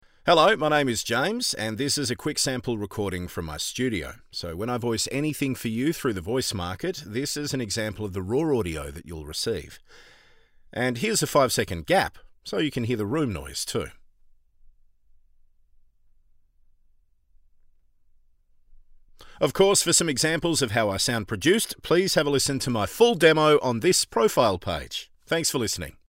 • Studio Sound Check
• Neumann TLM103 / Rode NT2a / Sennheiser MKH416
• Focusrite isa one preamp